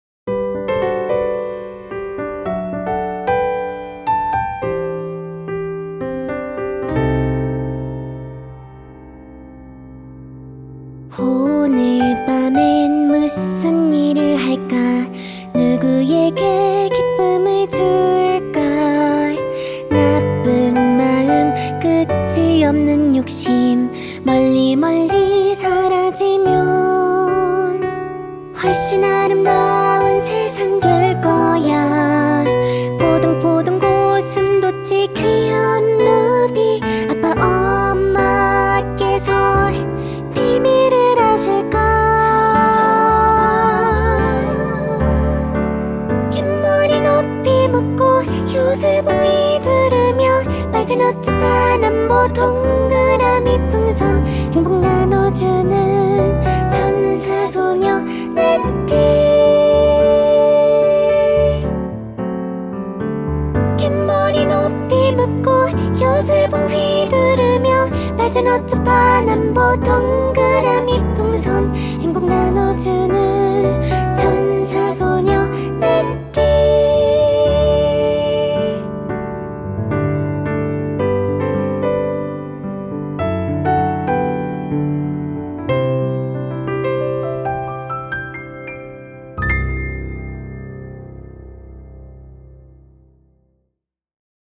[02:28] 화음 내가 한곤디 ㅠㅠ